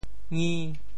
仪（儀） 部首拼音 部首 亻 总笔划 5 部外笔划 3 普通话 yí 潮州发音 潮州 ngi5 文 中文解释 仪 <名> (形声。
ngi5.mp3